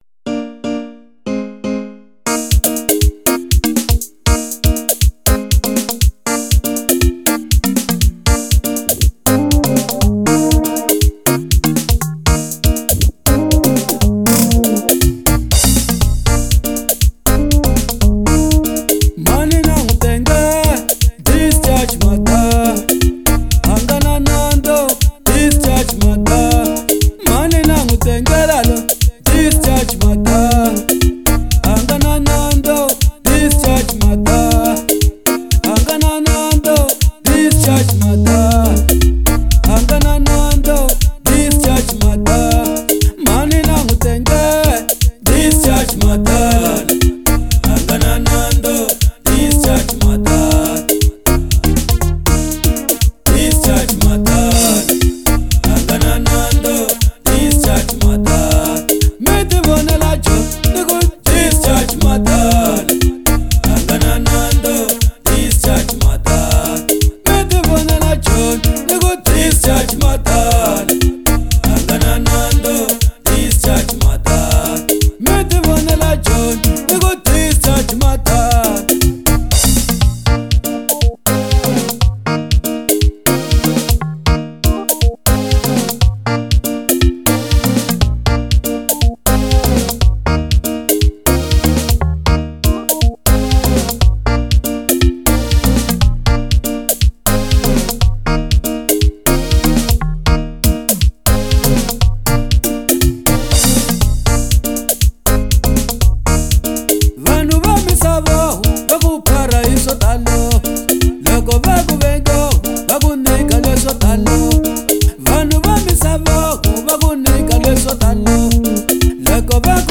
04:47 Genre : Xitsonga Size